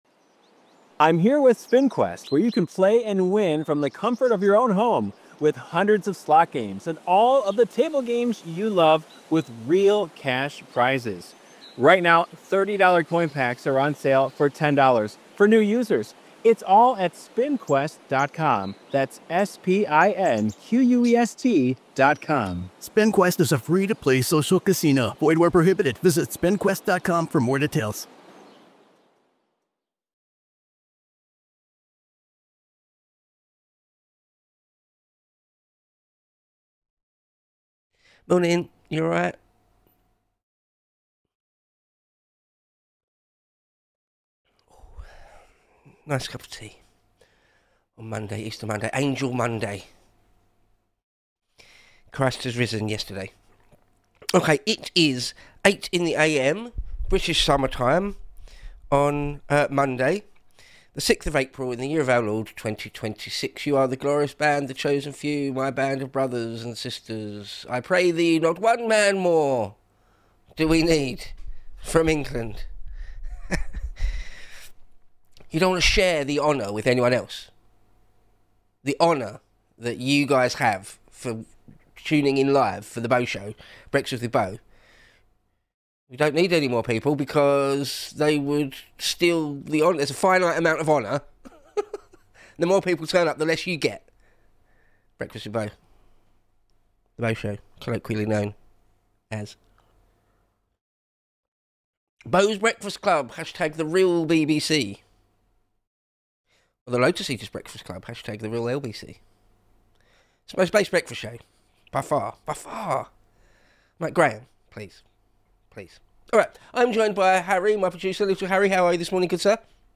Live 8-9am GMT on weekdays.